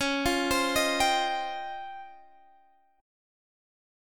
DbM#11 Chord
Listen to DbM#11 strummed